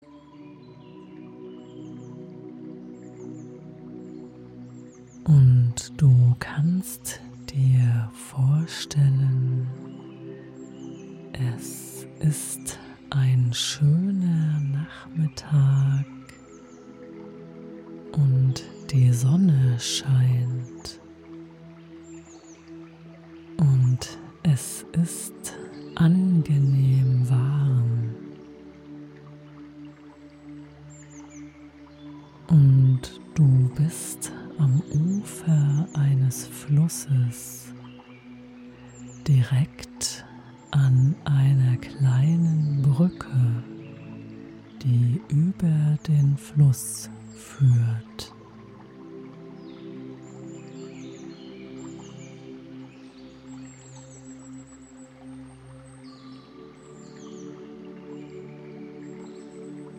Fantasiereise Auf der Brücke am Fluss als mp3-Download